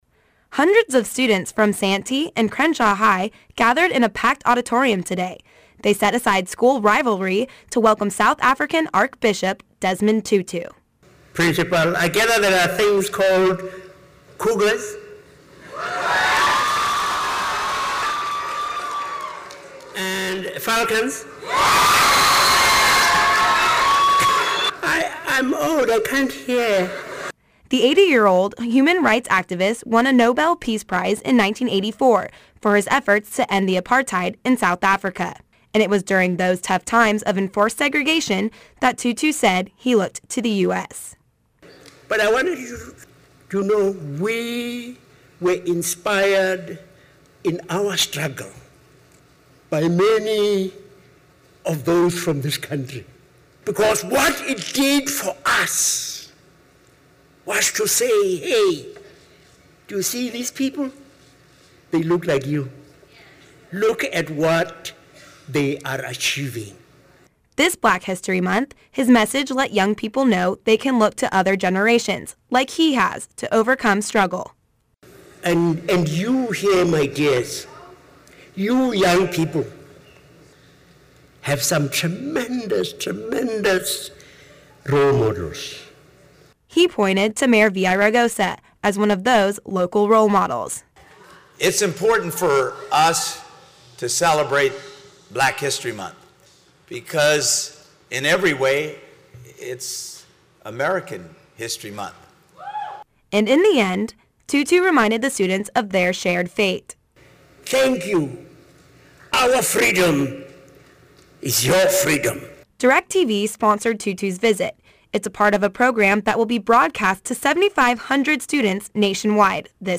Hundreds of students from the Santee and Crenshaw high schools gathered in a packed auditorium to welcome Nobel Peace Prize Winner, Desmond Tutu, in honor of Black History Month.